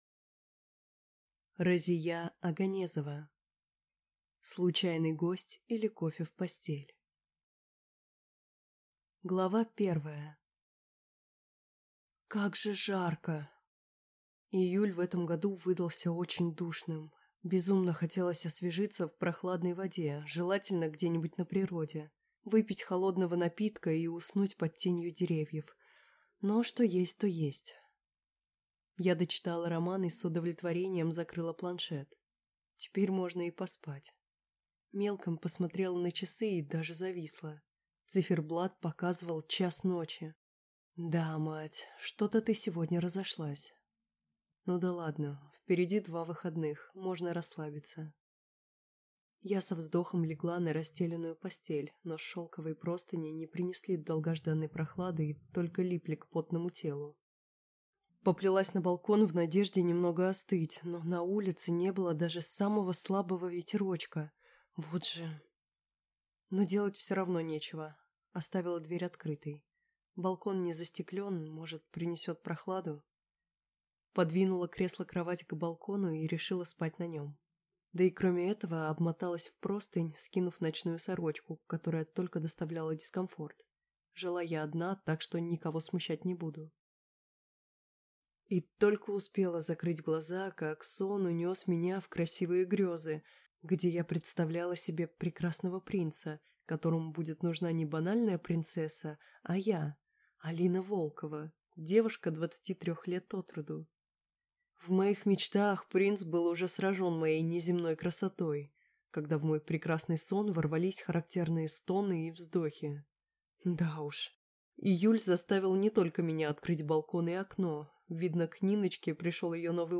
Аудиокнига Случайный гость или кофе в постель | Библиотека аудиокниг
Прослушать и бесплатно скачать фрагмент аудиокниги